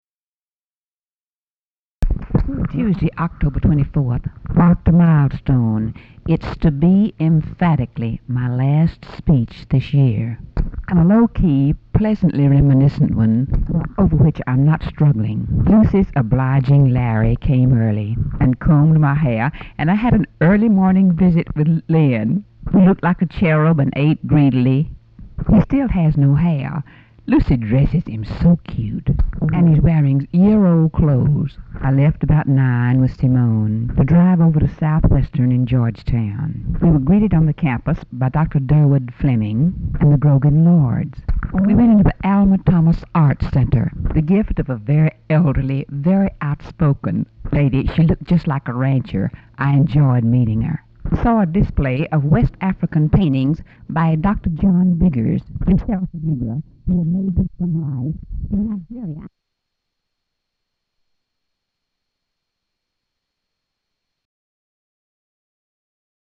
Audio diary and annotated transcript, Lady Bird Johnson, 10/24/1967 (Tuesday) | Discover LBJ
Format Audio tape